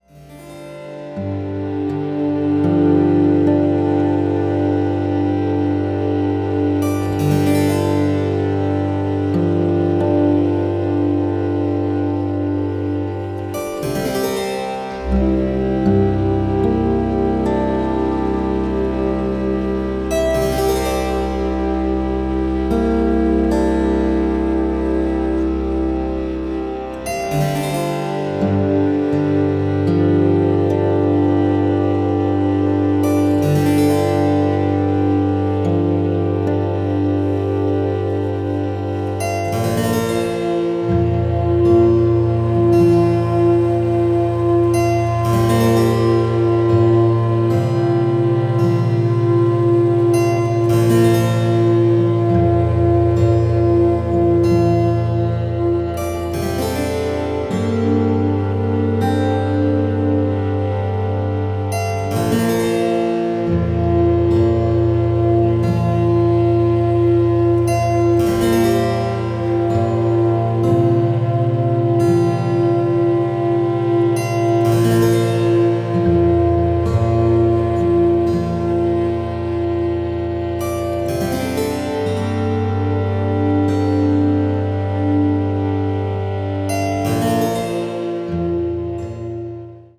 bass clarinet
traverso flute
viola